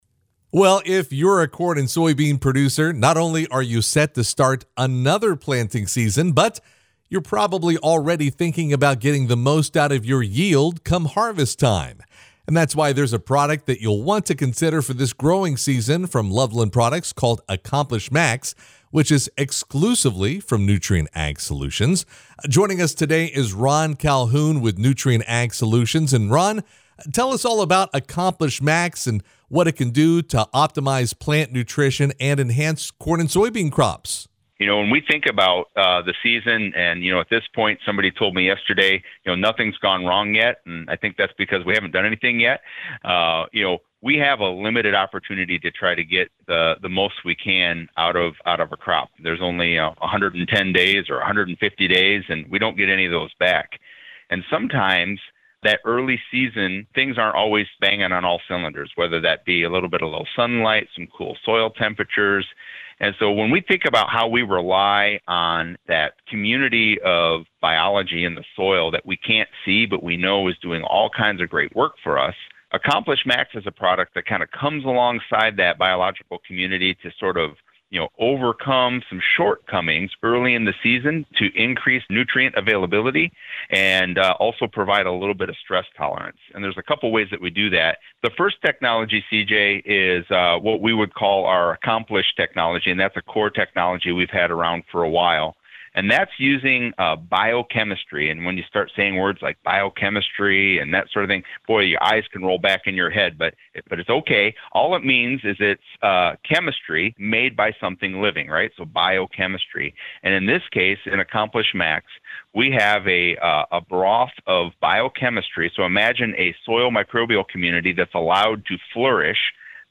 full conversation